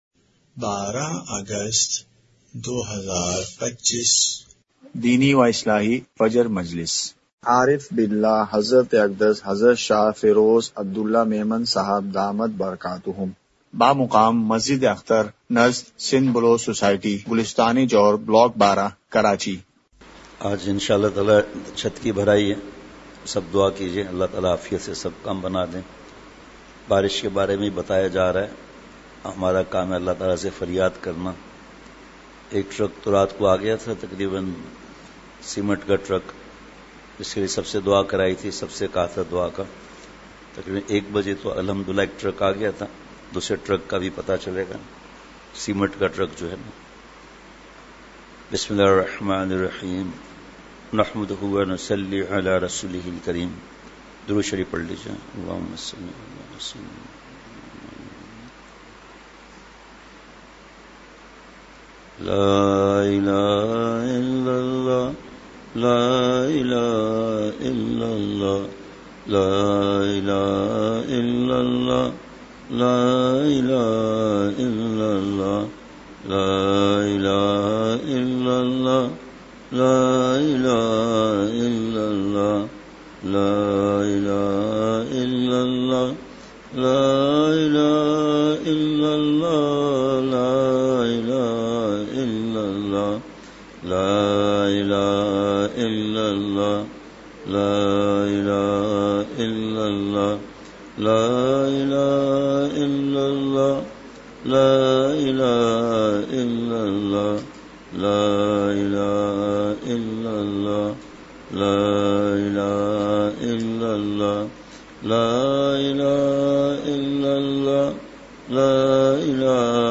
مقام:مسجد اختر نزد سندھ بلوچ سوسائٹی گلستانِ جوہر کراچی